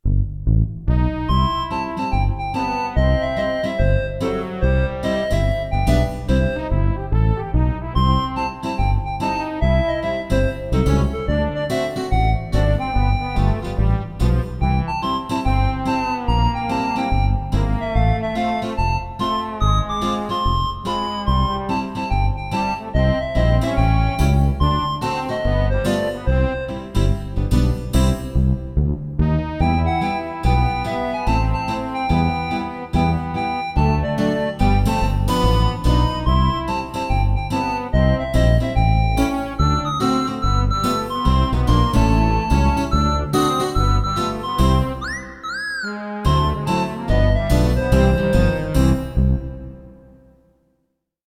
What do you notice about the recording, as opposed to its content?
MIDI version